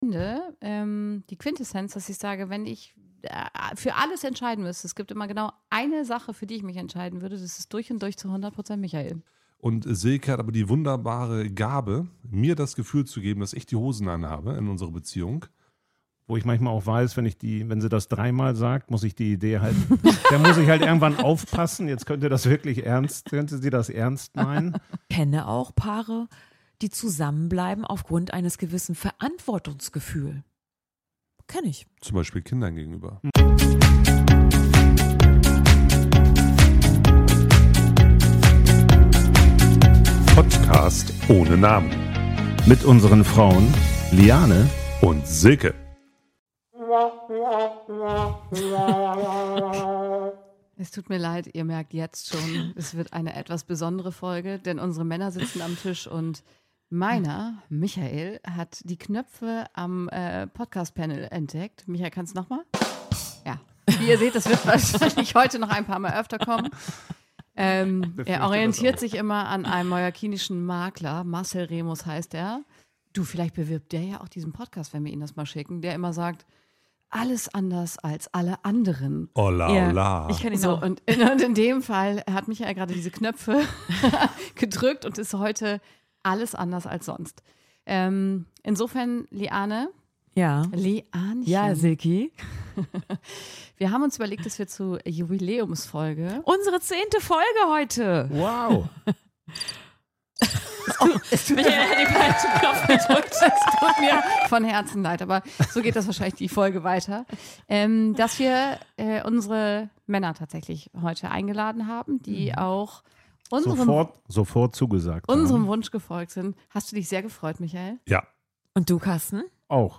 Dieses Mal reden unsere Männer mit und auch sie nehmen kein Blatt vor dem Mund. Beziehung heißt Arbeit, an sich selbst und miteinander. In dieser besonderen Folge geben wir euch ungeschnittene Einblicke in unsere Beziehungsarbeit, was uns zusammenhält und unsere Sicht nach außen.